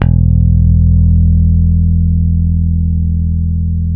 -JP PICK E 2.wav